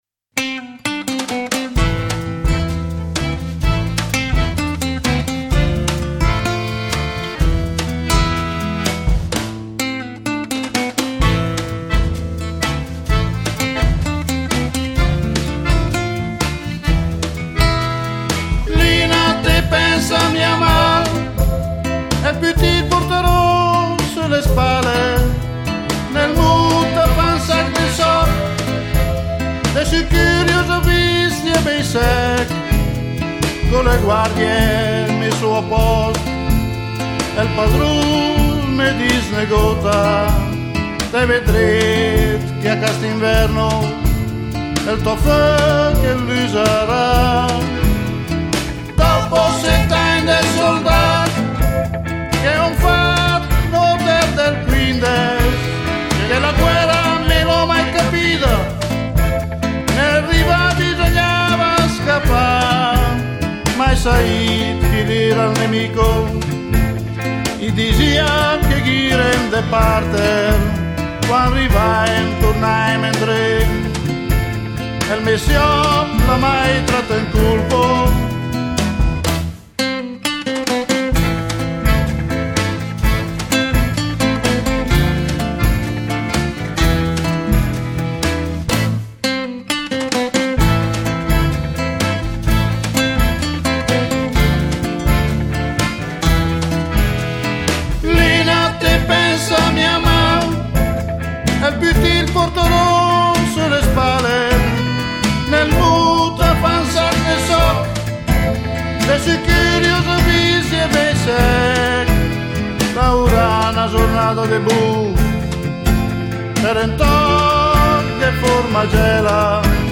"òn sàch de sòch sèch", canzone scritta ed interpretata per il cd "Goi de contàla?"
chitarra elettrica
basso elettrico
batteria